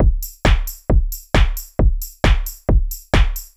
GAR Beat - Mix 2.wav